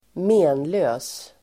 Ladda ner uttalet
Uttal: [²m'e:nlö:s]